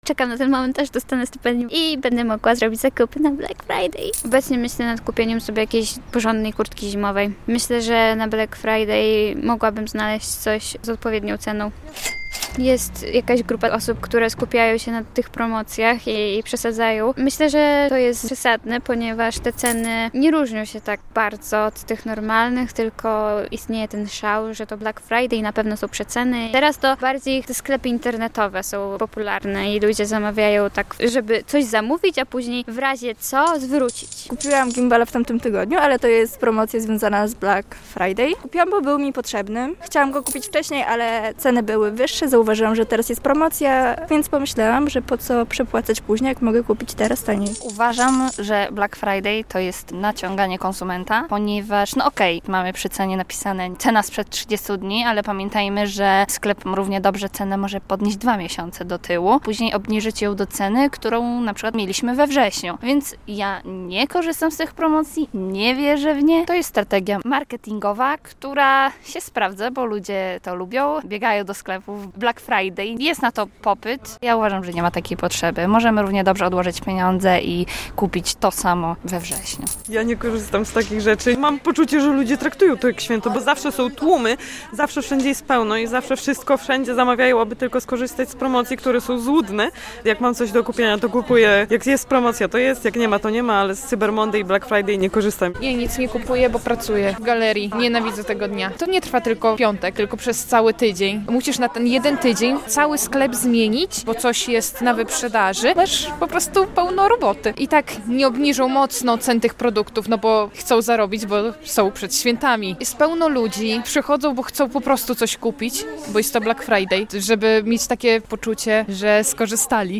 Black Friday - czy to się opłaca? [sonda]